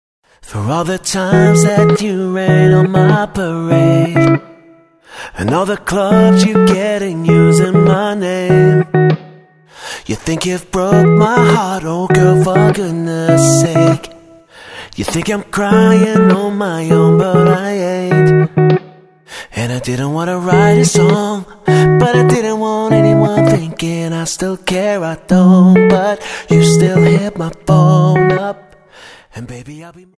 --> MP3 Demo abspielen...
Tonart:E Multifile (kein Sofortdownload.
Die besten Playbacks Instrumentals und Karaoke Versionen .